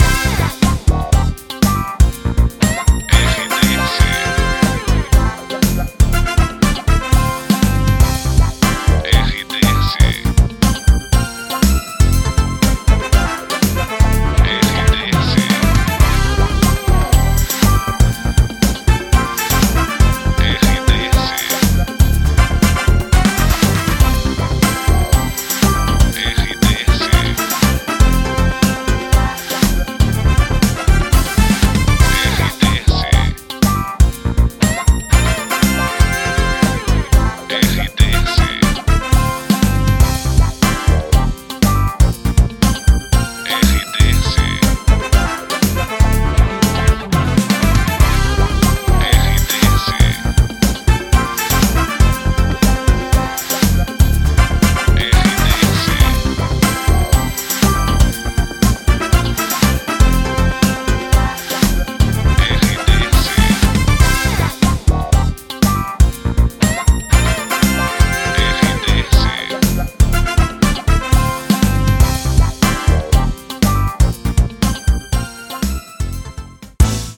Trilha para locução